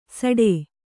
♪ saḍē